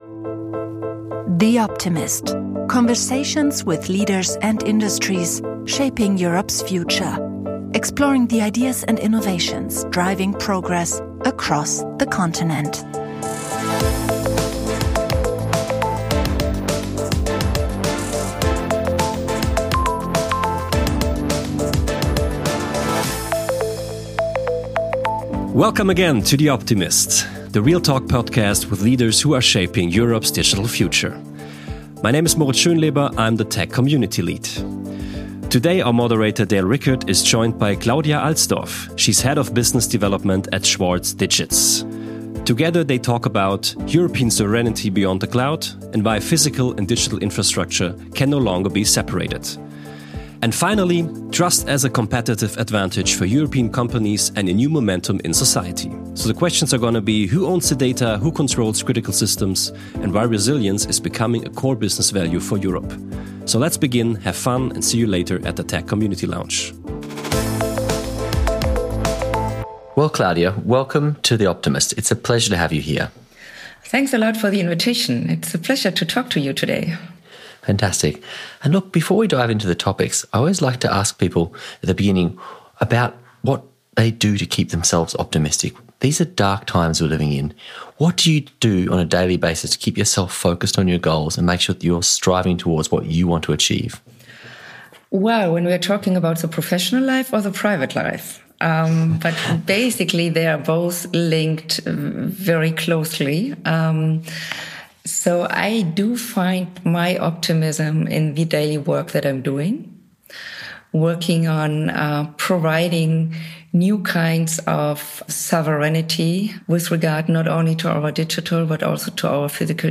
This conversation challenges common assumptions about cloud, data, and security — and offers a pragmatic roadmap for business leaders who want resilience without dogma.